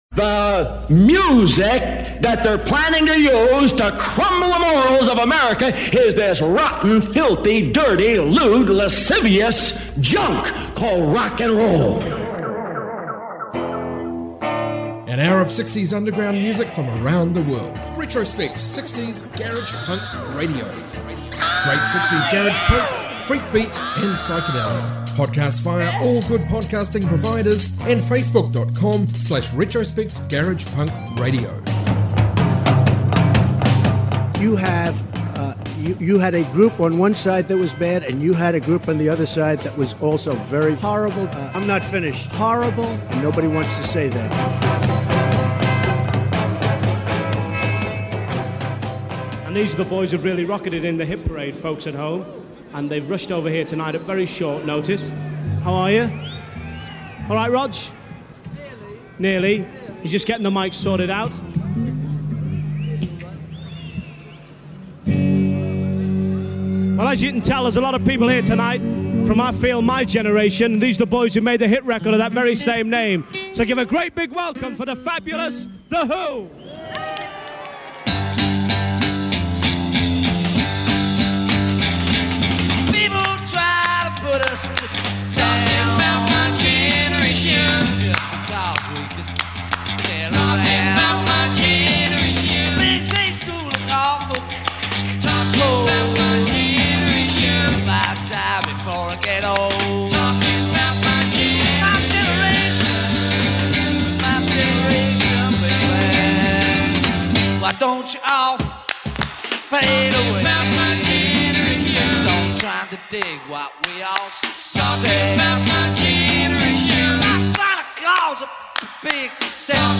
60s global garage rock